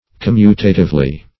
commutatively - definition of commutatively - synonyms, pronunciation, spelling from Free Dictionary
Com*mut"a*tive"ly, adv.